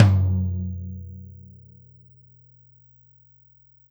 Tom Shard 10.wav